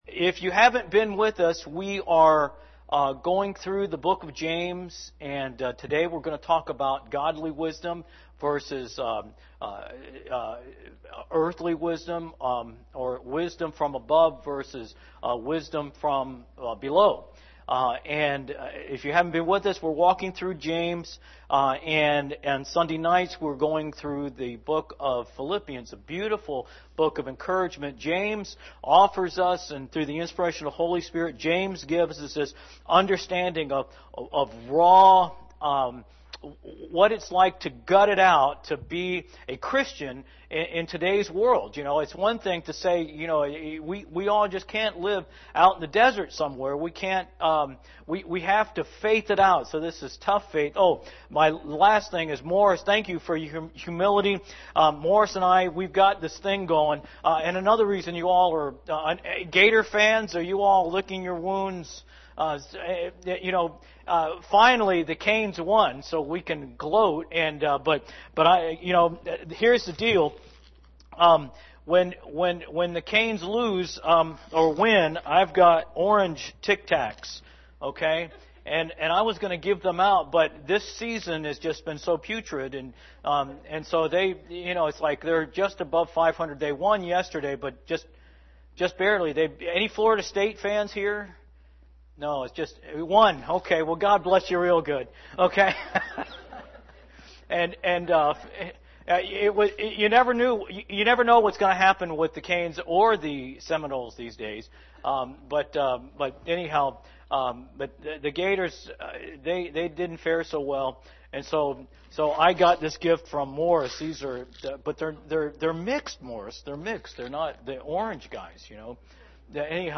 Morning Sermon
sermon11-3-19am.mp3